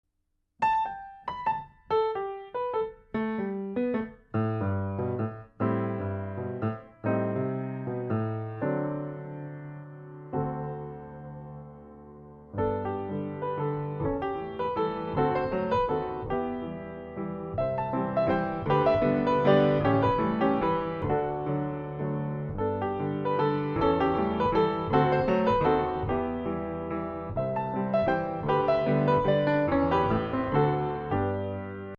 Pianoforte